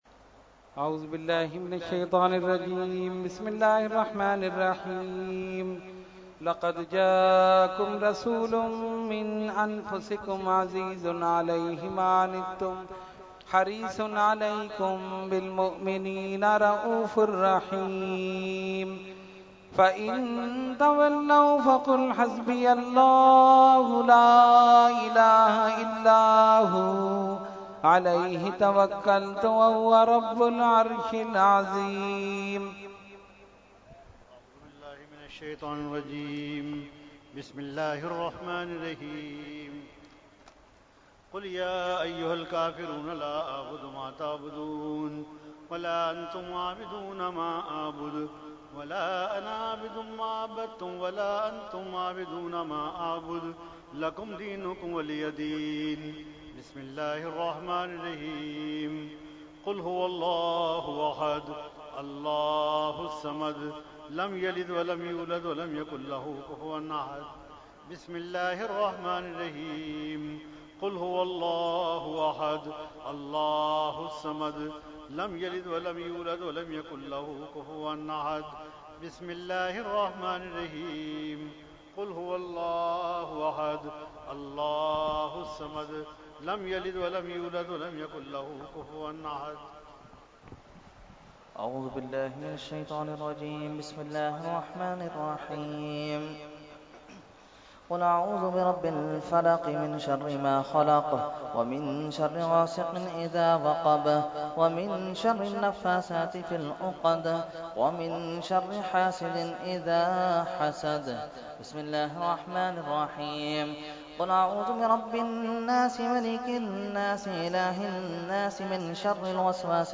Category : Fatiha wa Dua | Language : ArabicEvent : Urs Ashraful Mashaikh 2017